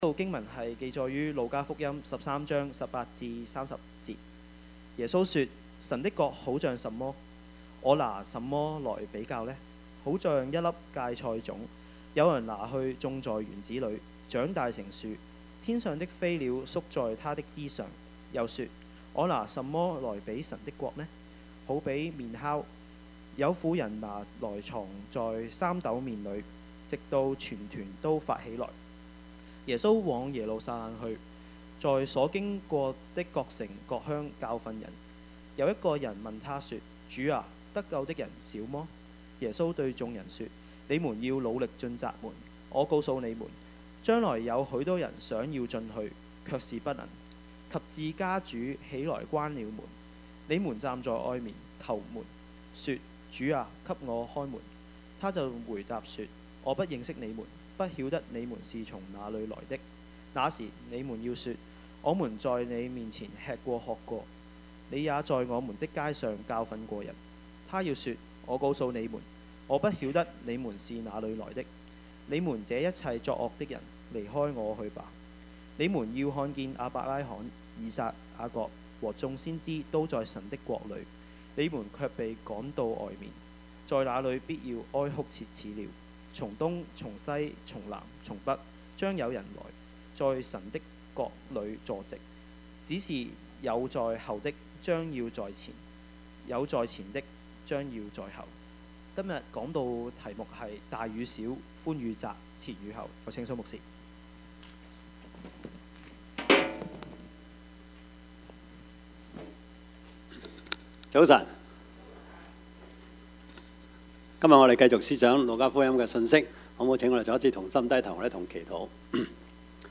Passage: 路加福音十三 18-30 Service Type: 主日崇拜
Topics: 主日證道 « 勞苦與喜樂 狐狸與小雞 »